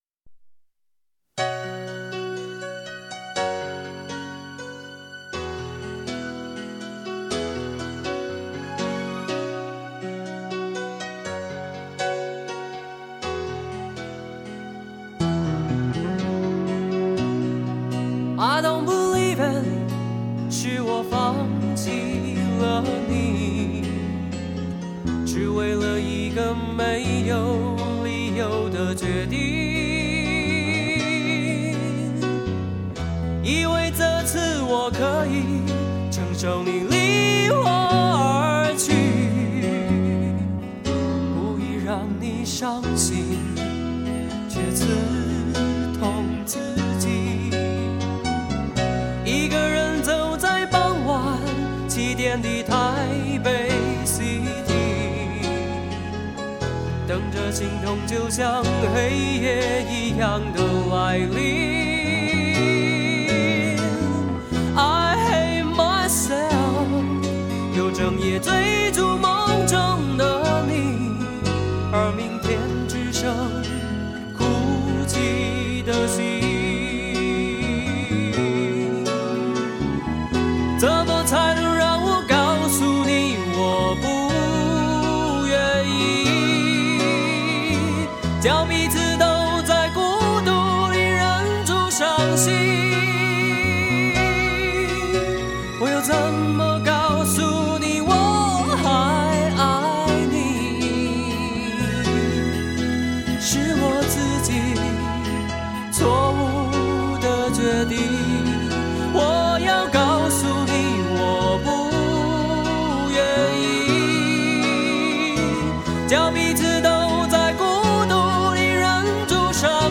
歌曲表达上选择简单的乐器伴奏及强调声音的质感